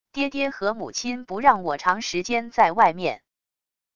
爹爹和母亲不让我长时间在外面wav音频生成系统WAV Audio Player